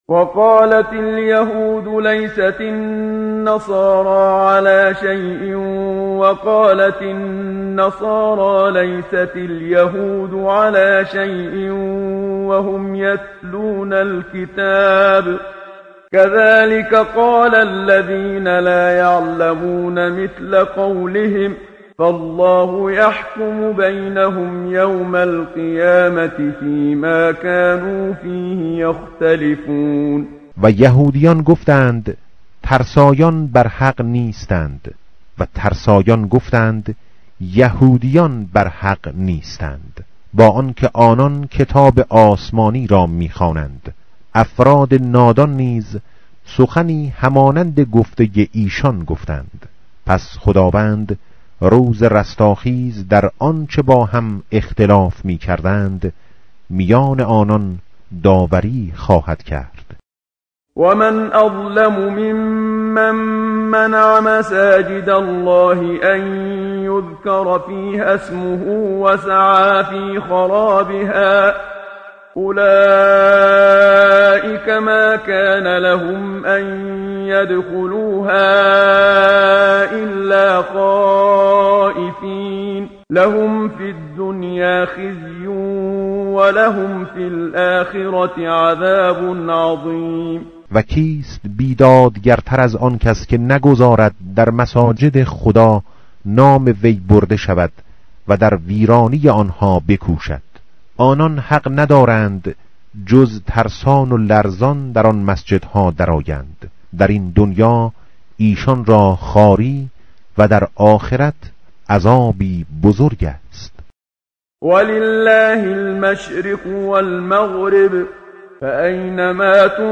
متن قرآن همراه باتلاوت قرآن و ترجمه
tartil_menshavi va tarjome_Page_018.mp3